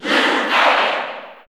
Category: Crowd cheers (SSBU) You cannot overwrite this file.
Lucario_Cheer_English_SSB4_SSBU.ogg